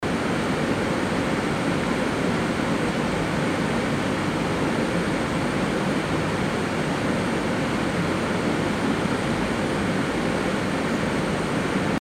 Звуки кондиционера
Звук работающего кондиционера белый шум